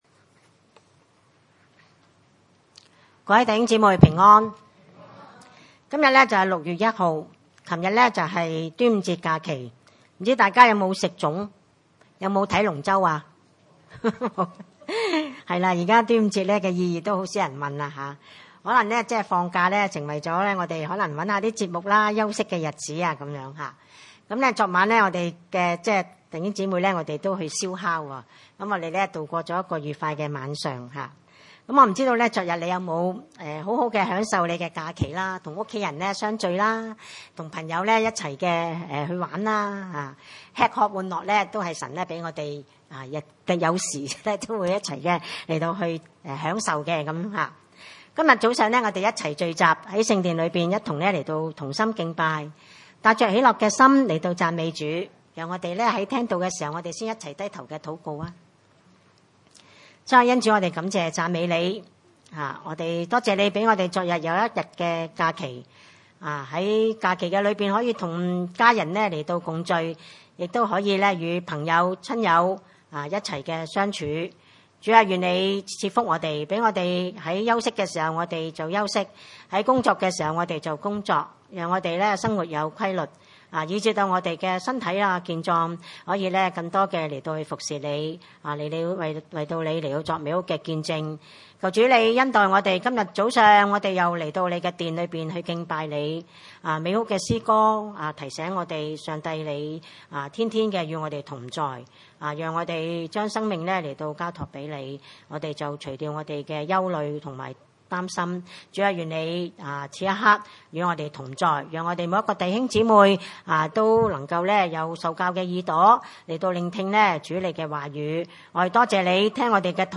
腓立比書4：2-9 崇拜類別: 主日午堂崇拜 2.